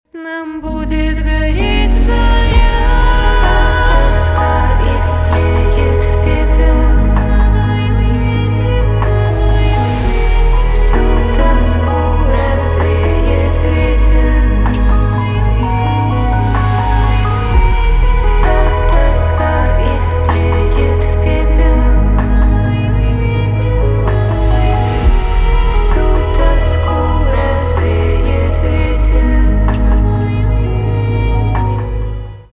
инди
спокойные , грустные
чувственные